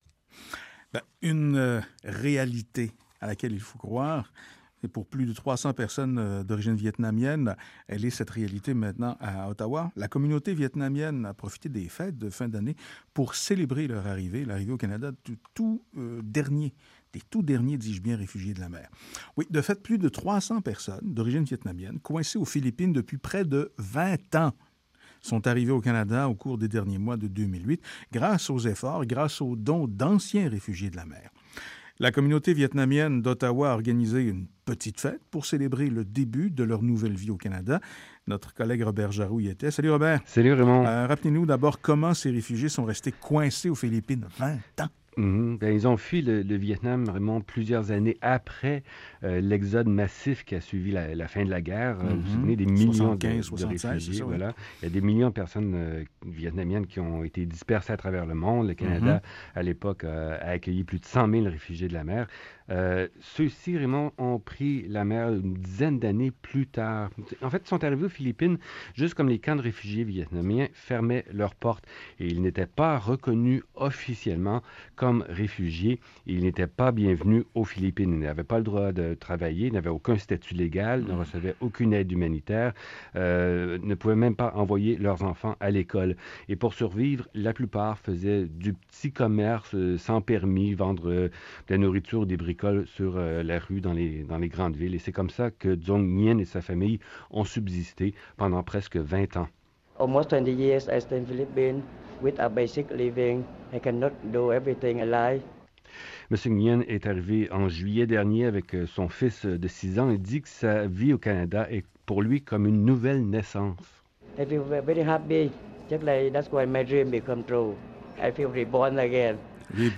par | Classé dans : Archives, Communauté vietnamienne, Reportages | 1